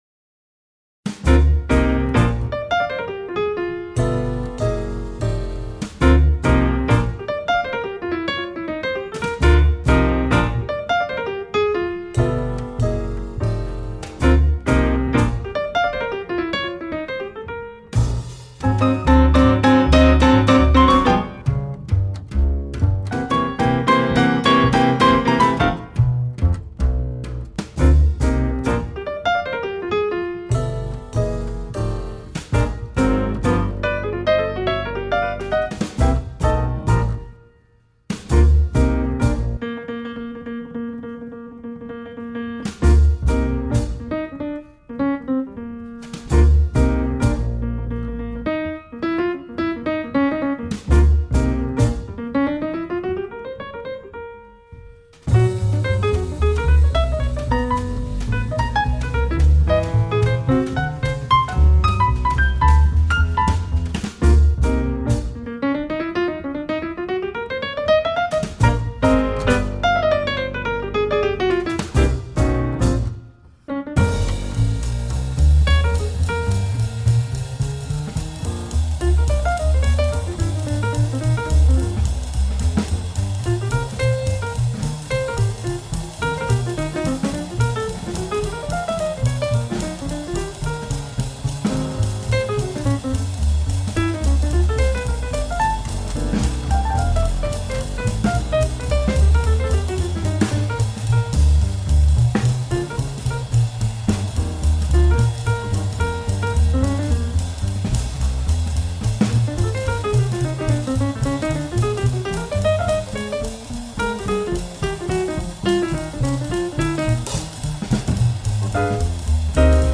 uno de los locales obligados para los amantes del jazz.